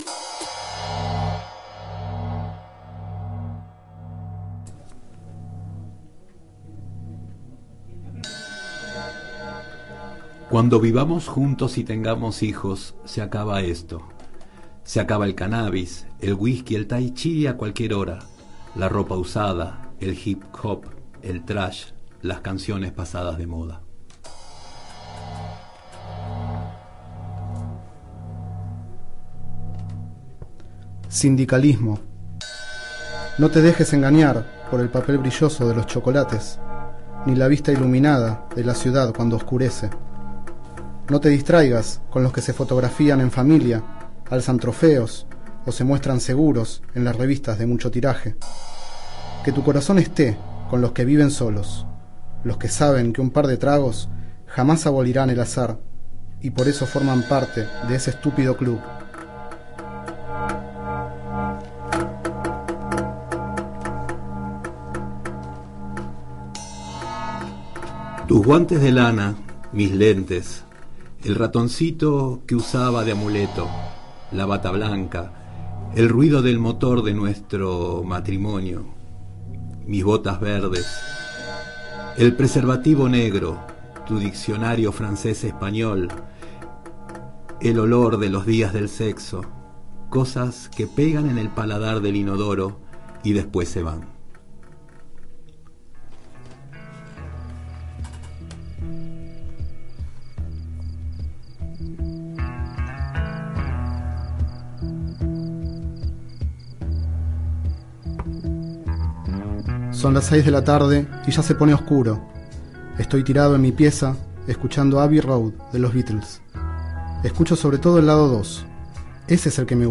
Este es el 2º micro radial, emitido en el programa Enredados, de la Red de Cultura de Boedo, por FMBoedo, realizado el 29 de junio de 2010, sobre los libros Horla city y otros y Ocio, de Fabián Casas.
Durante el micro leemos algunos poemas «al azar» de la poesía reunida de Fabián Casas y el fragmento inicial de la novela breve Ocio.
De fondo se escuchan Sun King y I want you (she’s so heavy), temas #10 y #6 respectivamente del Disco Abbey Road de The Beatles.